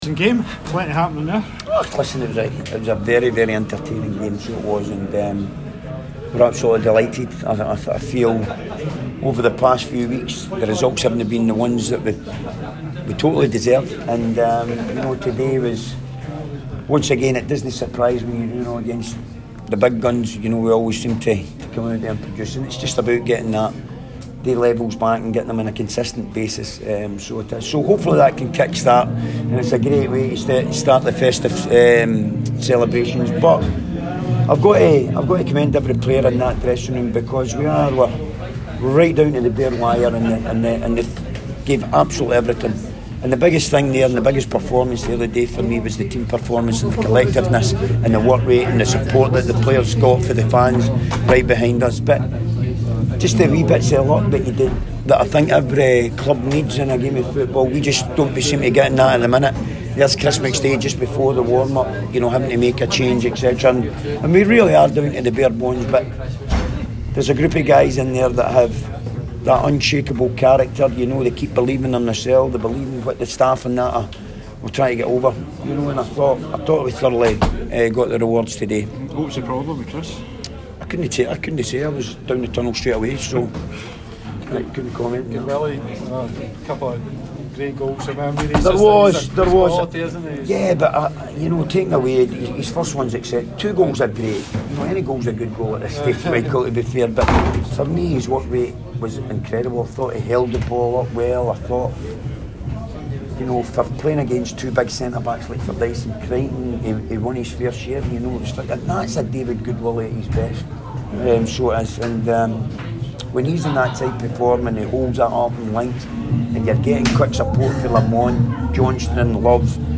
press conference after the Ladbrokes League 1 match.